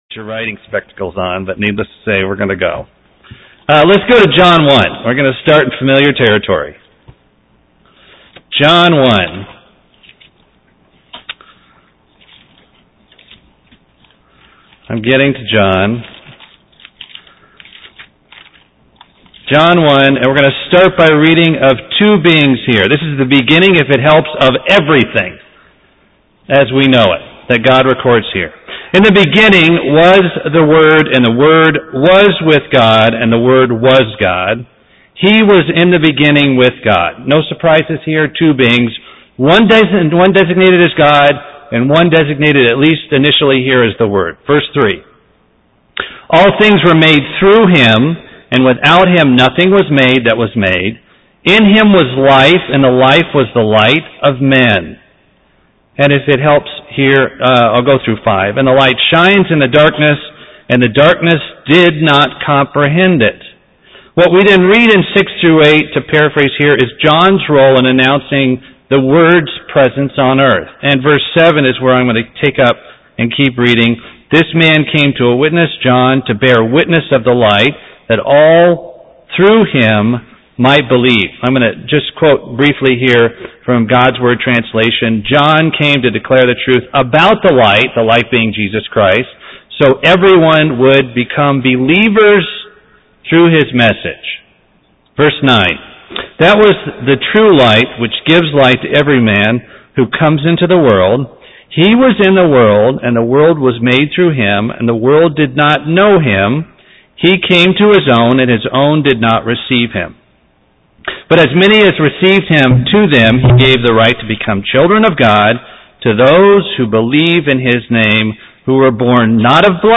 Given in Buford, GA
UCG Sermon Studying the bible?